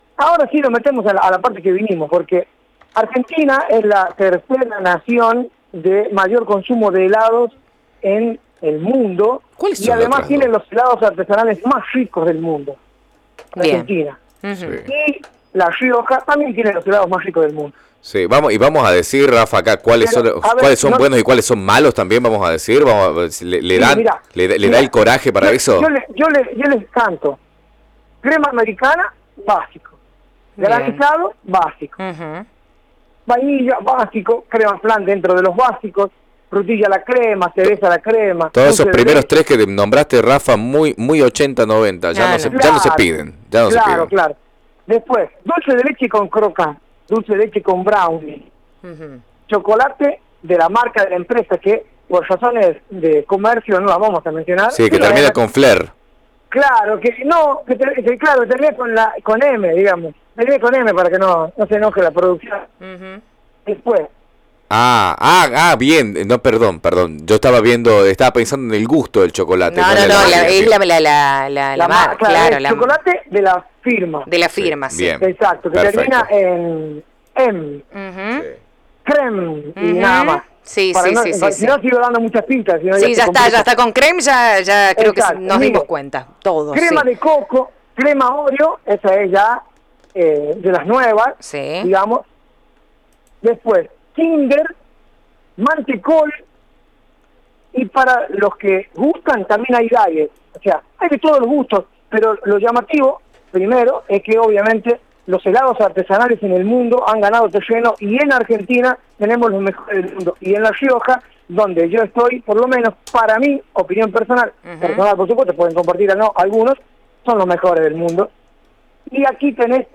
Radio UNLaR realizó un informe sobre cuánto consumimos, los sabores más elegidos, y precios riojanos de helados artesanales. Cabe destacar que Argentina se posicionó en el 3° puesto a nivel mundial por tener los mejores helados, el podio lo completan Italia y Alemania.